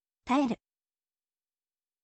taeru